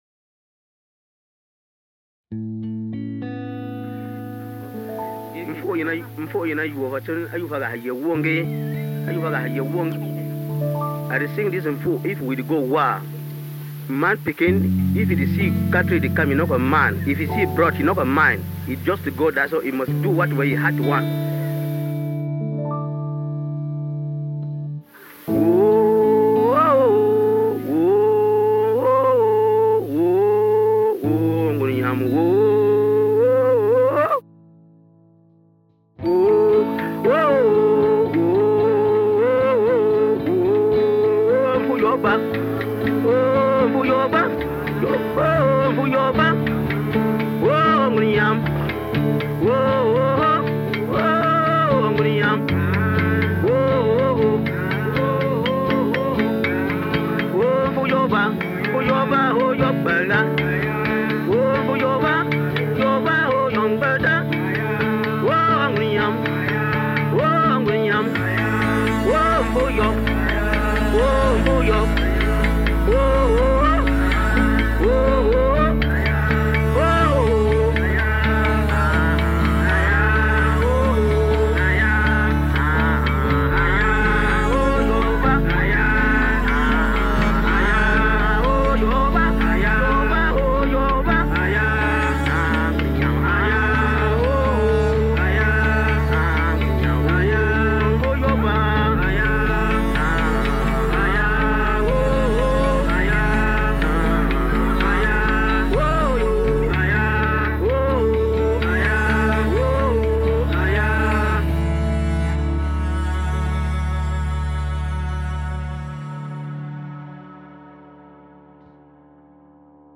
I struggled quite a bit at the beginning, as the field recording has both harmonic & rhythmic structure which varies in tempo quite a bit… so I wasn’t sure how to approach this initially.
After some thought and a fair bit of trial and error, I decided to pick up my guitar (and play the piano) and simply play along with them without overthinking it too much… so the tempo may be a little rough in places! Guitar and piano simply panned out to left/right, adding some bass/cello towards the end.
I noticed the call and response structure in the field recording, so tried to incorporate this as well at either end of the piece.